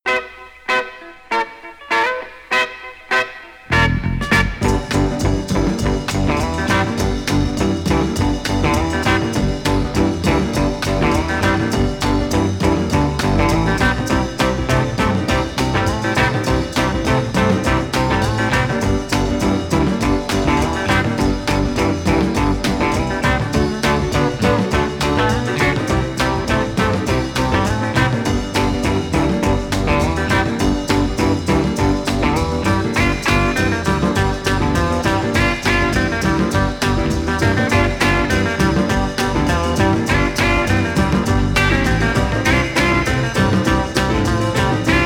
R&B, R&R, Jazz, Rock, Surf　USA　12inchレコード　33rpm　Stereo